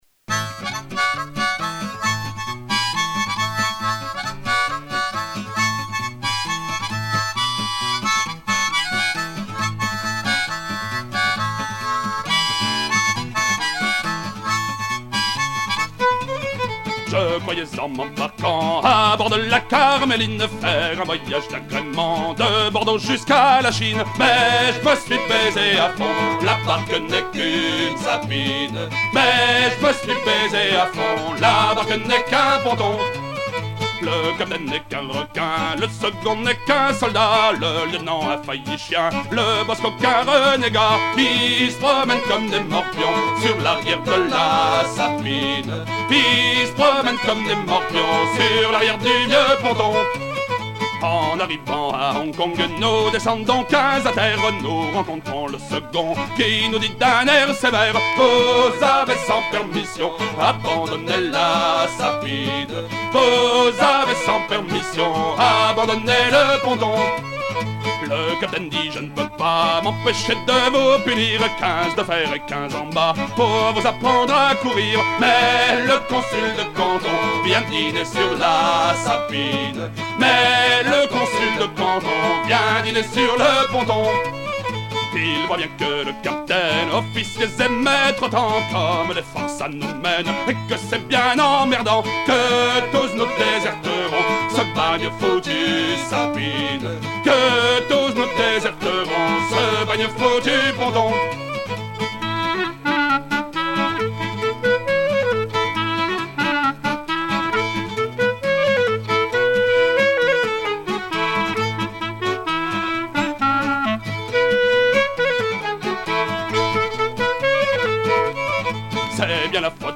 à hisser main sur main
Genre laisse